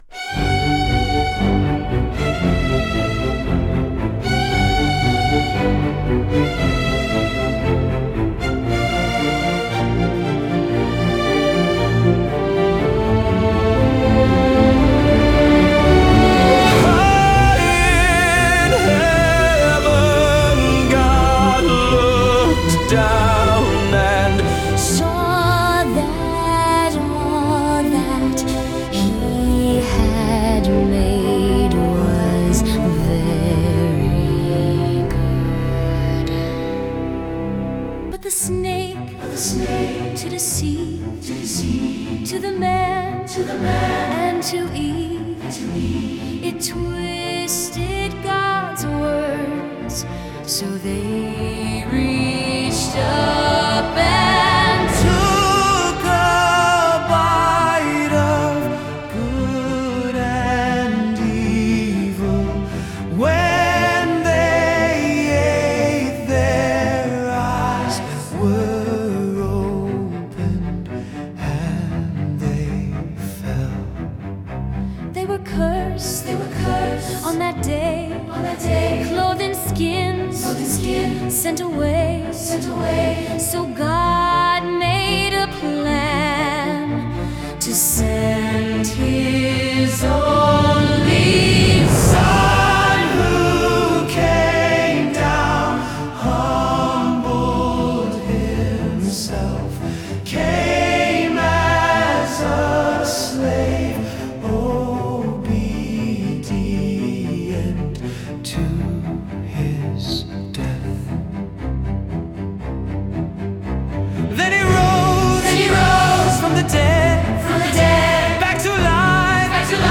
Redemption Roller Coaster (Classical, in the style of a Broadway musical)